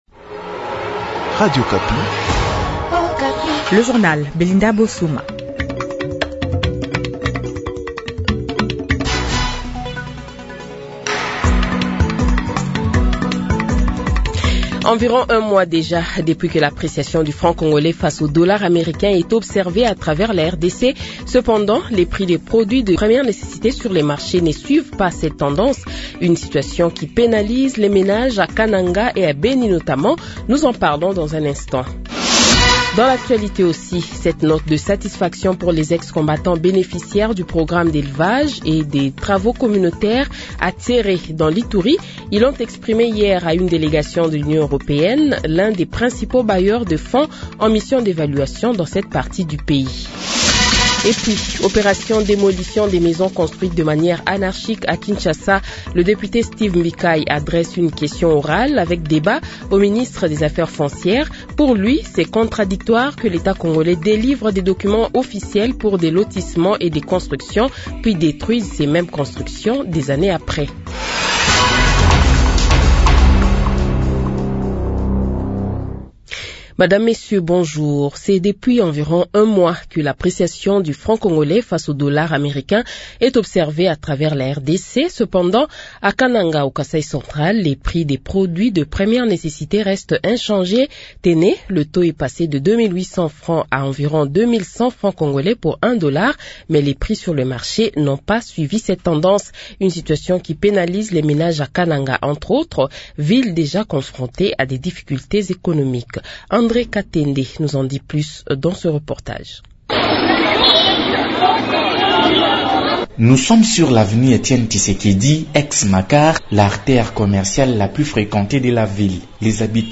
Le Journal de 7h, 17 Octobre 2025 :